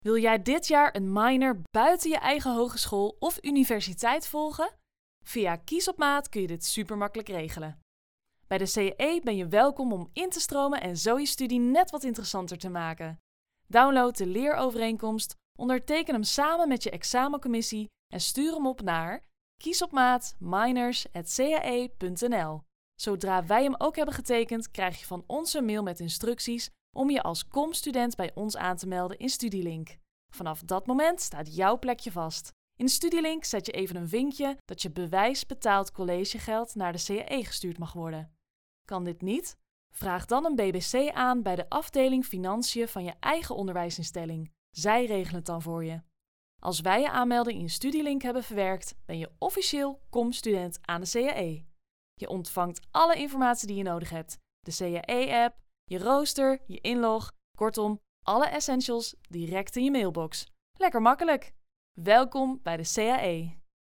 Young, Natural, Playful, Accessible, Friendly
Explainer